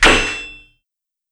"Metalic Sword Strike" From Mixkit